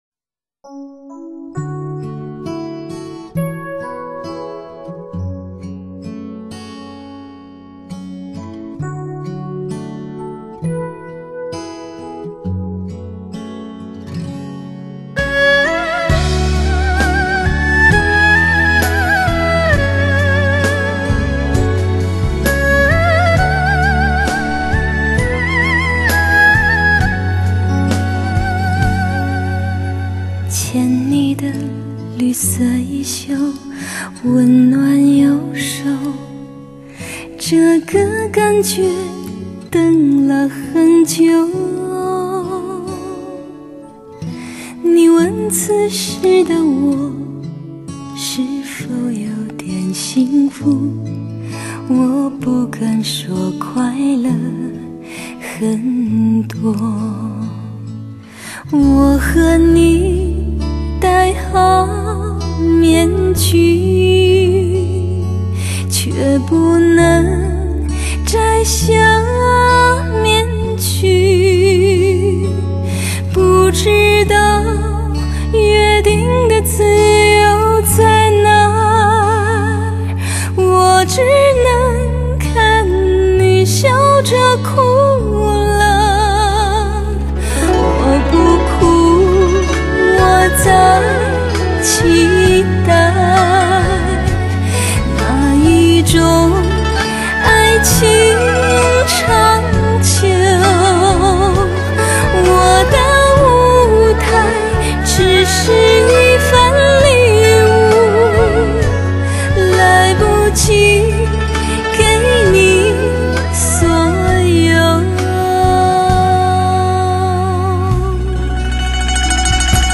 歌声清纯甜美，感情丰富，录音水准佳，配乐效果达五星级数，精彩演绎永恒经典金曲，不能忘怀的歌声，给你全新感受。